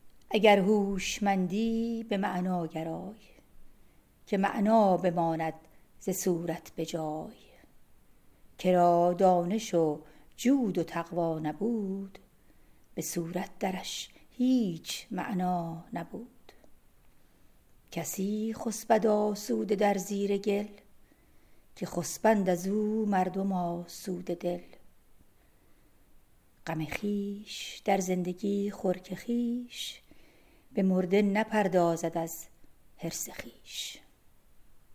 Poem recited